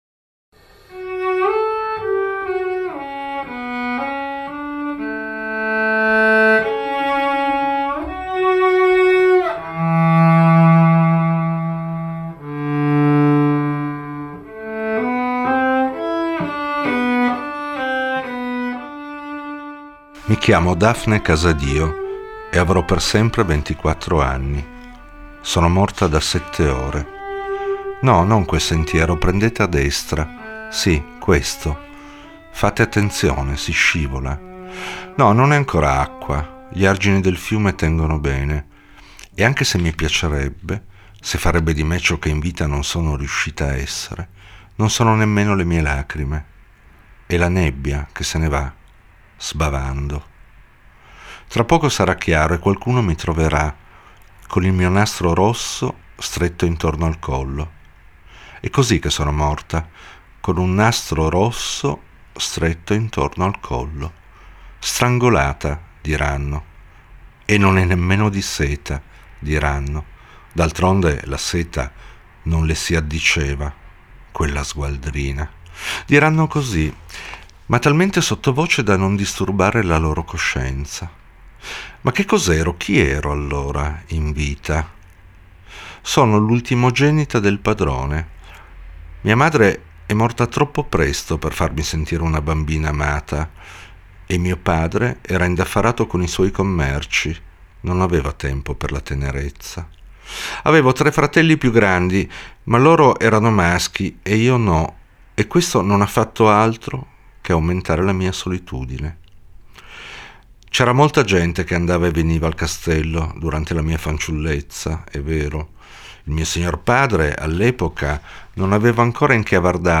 Formato Audiolibro
FINALMENTE ecco l’AUDIO LIBRO letto e interpretato dall’autore stesso